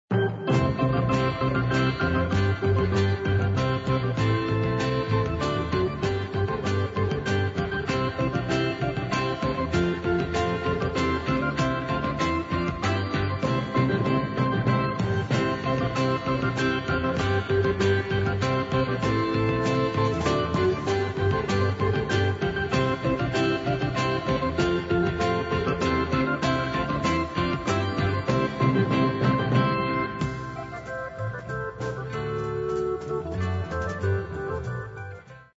skupina slovenské rockové legendy